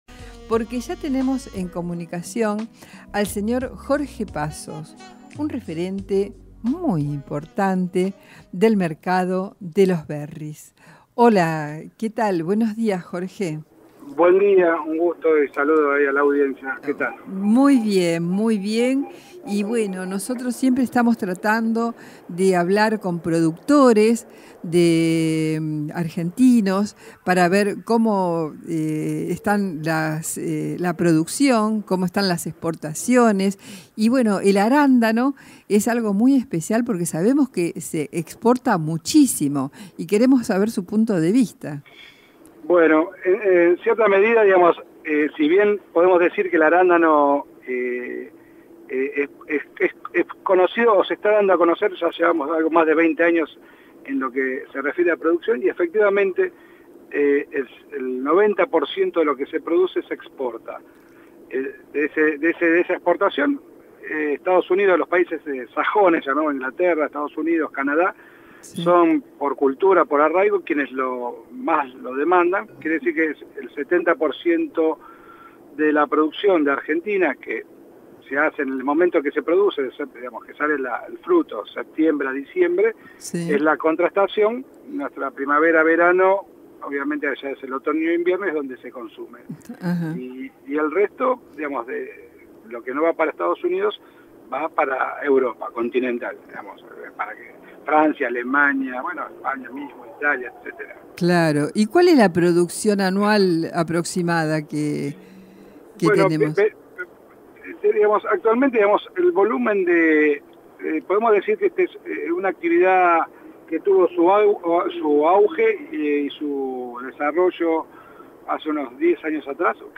en el programa de radio “El buen vivir”, que sale todos los sábados por AM Excelsior 940